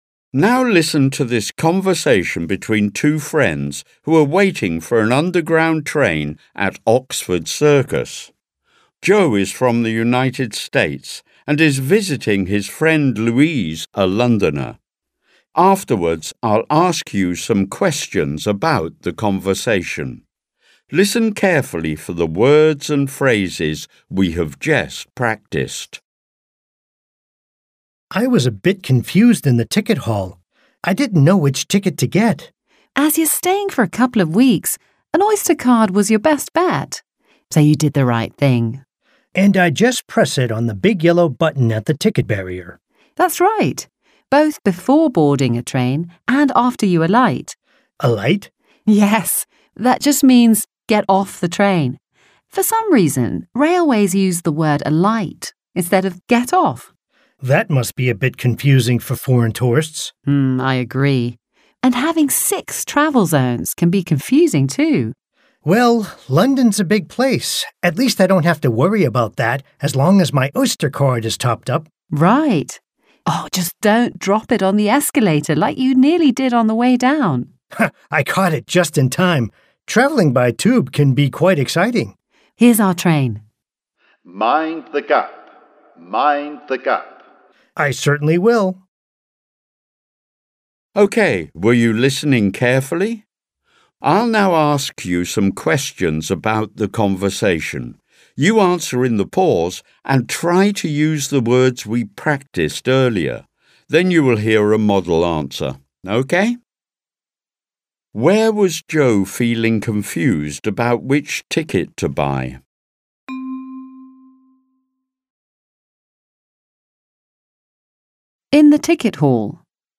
Exercise: Dialogue | ZSD Content Backend
Audio-Übung